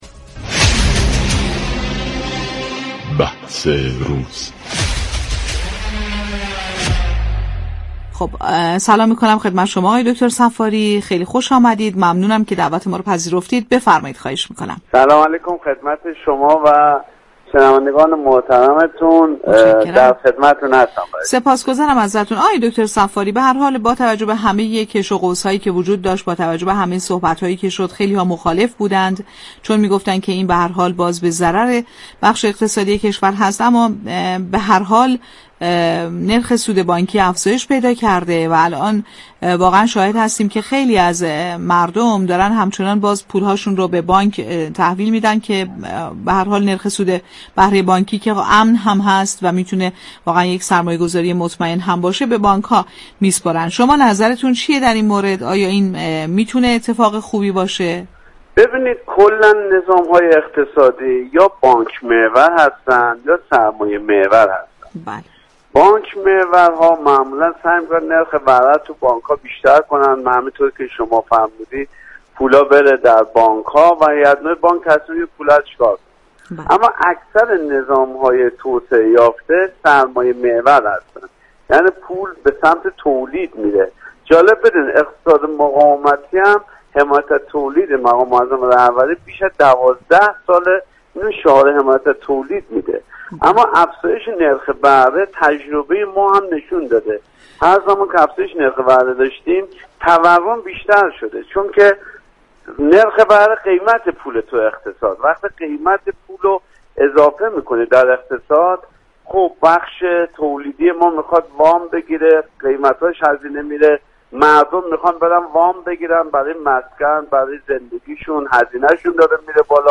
گفت و گو